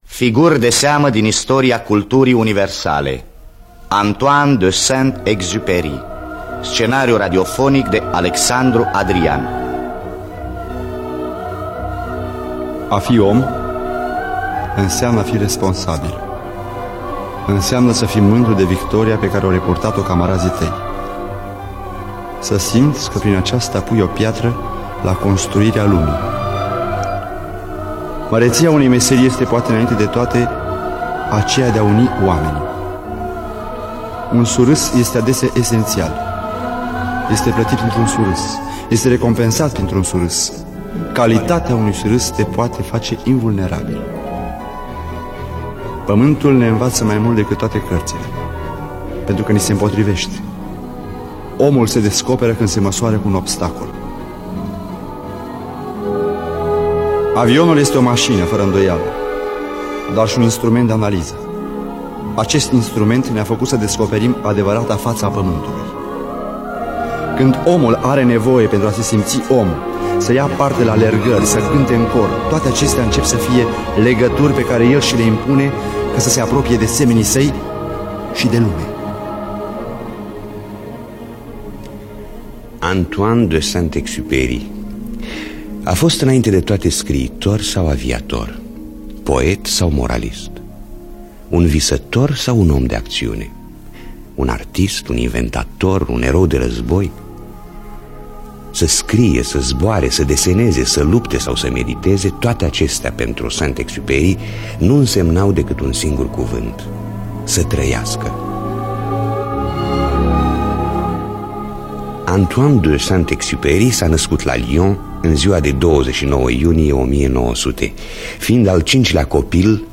Biografii, Memorii: Antoine de Saint-Exupery (1975) – Teatru Radiofonic Online